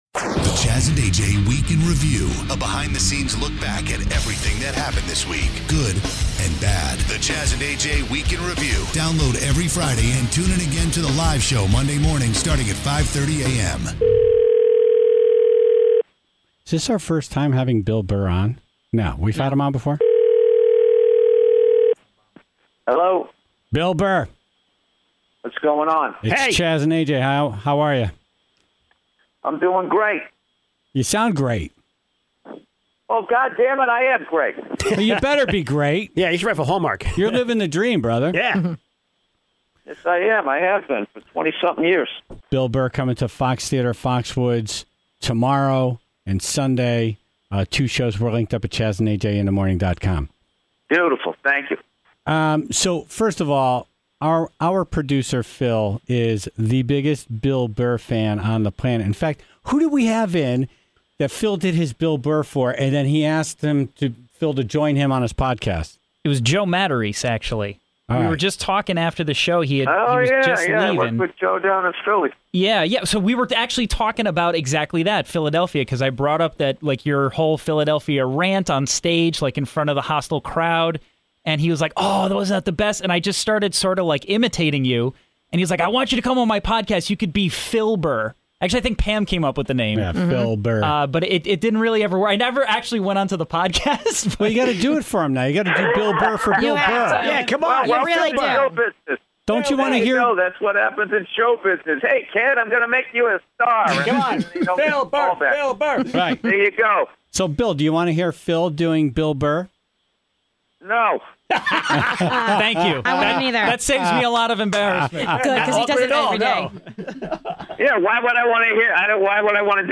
An extended, uncut interview with comedian Bill Burr, talking about his new series "F is for Family," and how his father gave him the inspiration for the show. Plus, how he became involved with "Breaking Bad," and if that will carry over to "Better Call Saul."